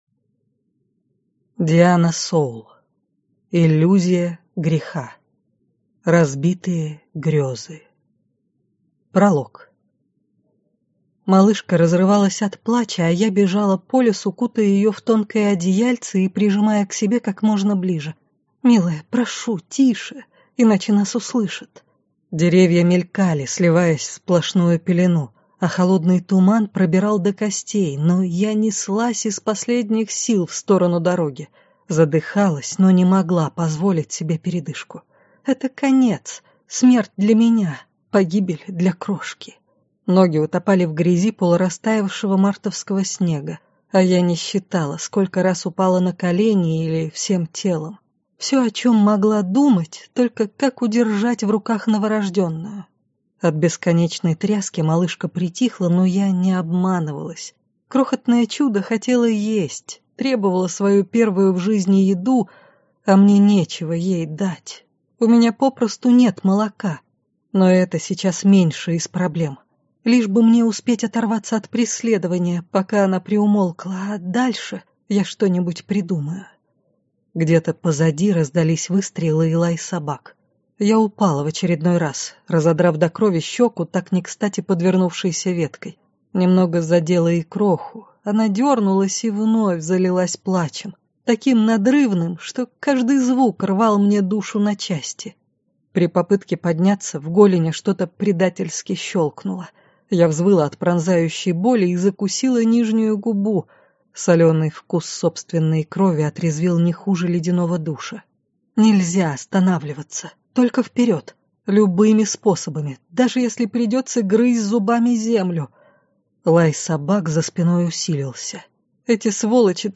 Аудиокнига Иллюзия греха. Разбитые грёзы | Библиотека аудиокниг